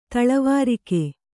♪ taḷavārike